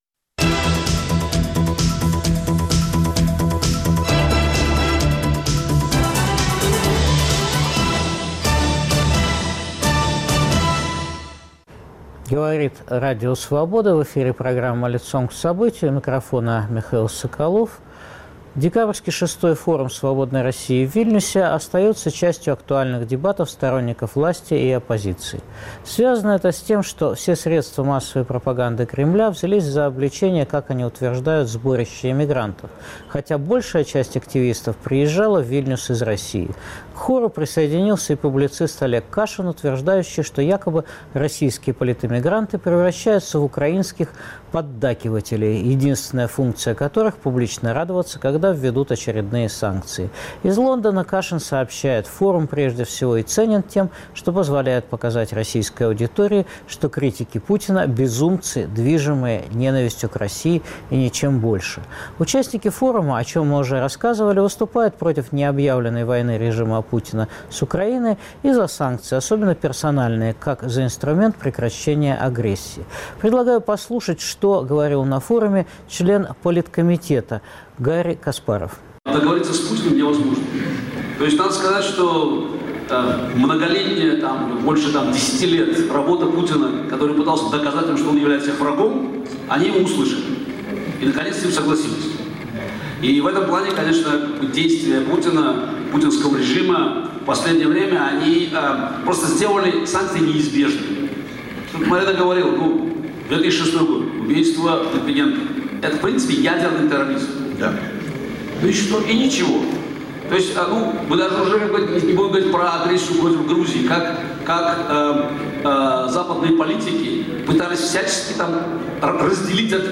Член Постоянного комитета Форума Свободной России Гарри Каспаров о санкциях, выборах и протесте. Эксклюзивное интервью Радио Свобода по итогам дискуссий в Вильнюсе.